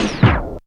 RECORD STOP.wav